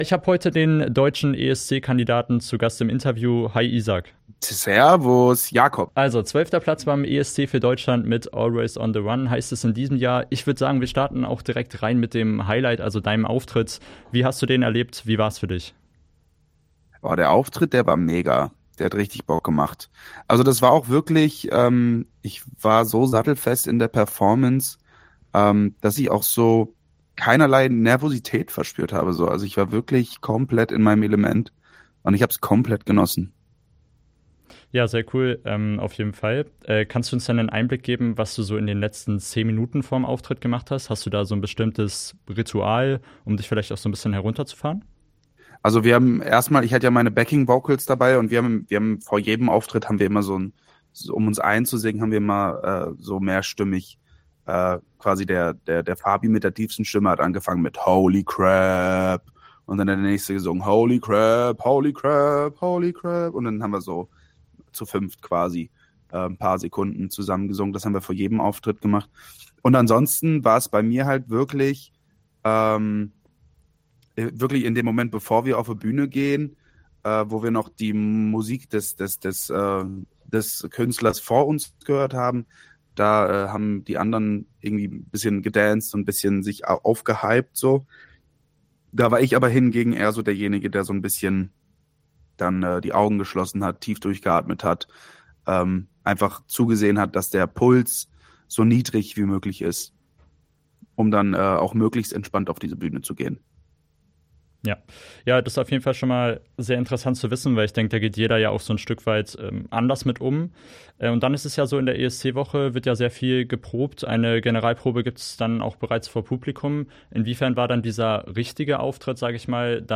ESC: Isaak im radio aktiv Gespräch
esc-isaak-im-radio-aktiv-gespraech.mp3